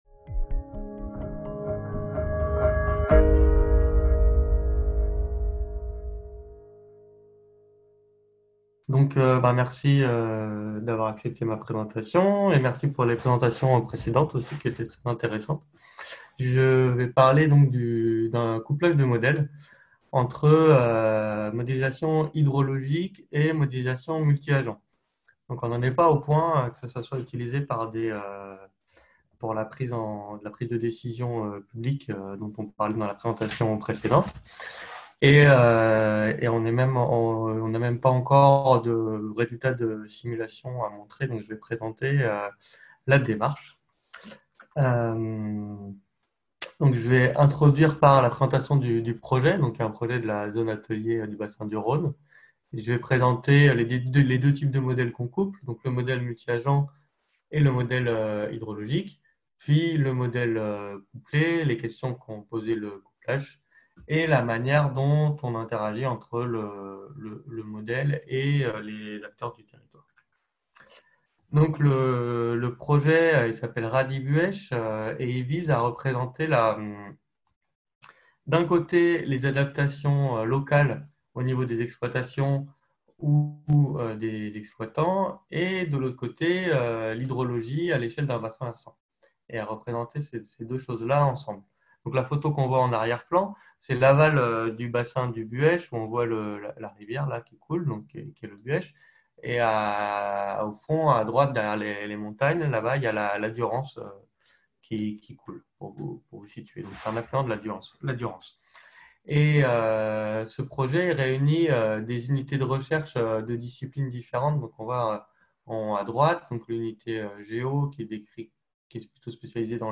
5e colloque des Zones Ateliers – CNRS 2000-2020, 20 ans de recherche du Réseau des Zones Ateliers SESSION 4 : Concepts et outils pour le suivi des socio-écosystèmes